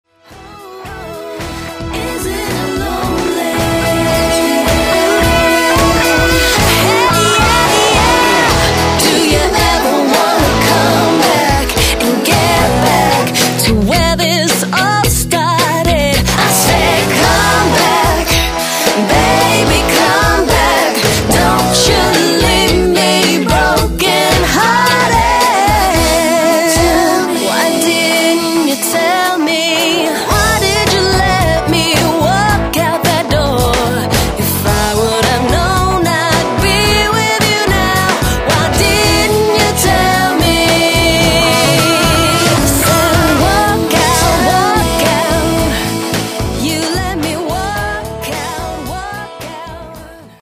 pop/r&b
contemporary sounds of guitar-based pop rock with
smooth and sexy r&b rhythms and reggae-tinged